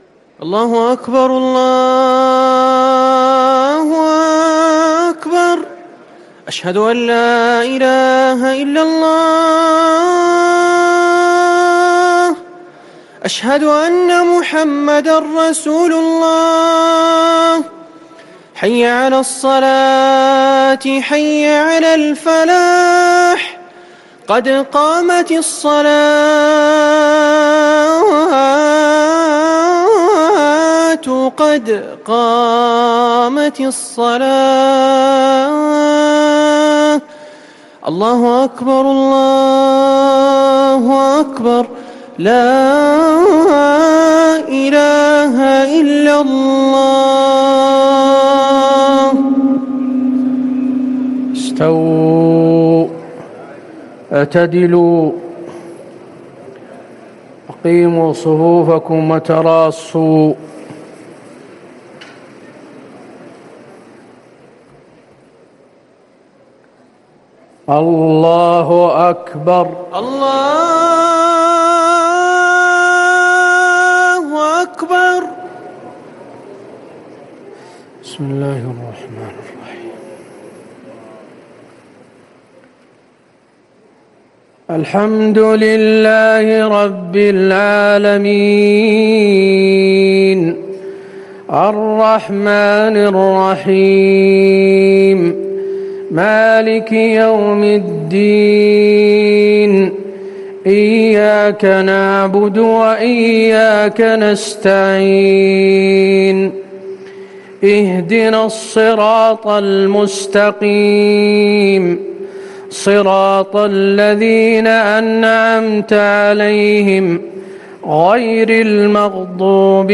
صلاة العشاء 3-9-1440هـ خواتيم سورة القمر 41-55 | Isha 8-5-2019 Prayer from Surat Al-Qamar > 1440 🕌 > الفروض - تلاوات الحرمين